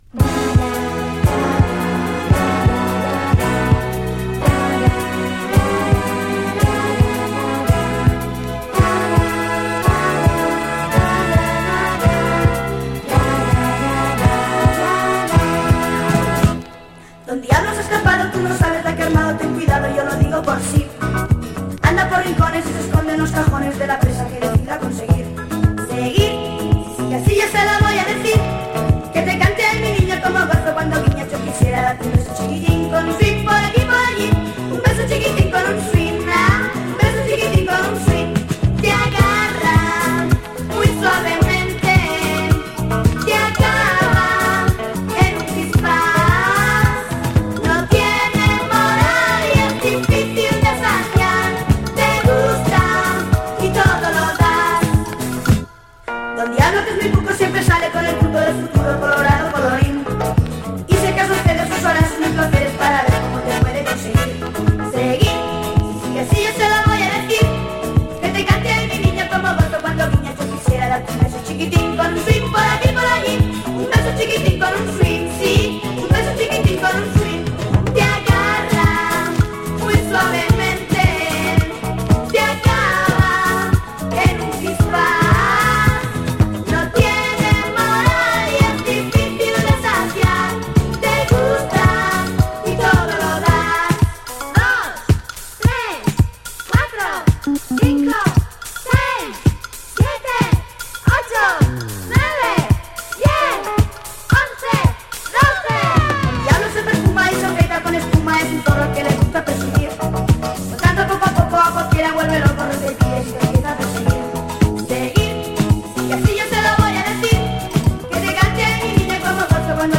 Soft Rock spain
メロウであどけないヴォーカルがとってもキュート。4つ打ちディスコアレンジも素晴らしい。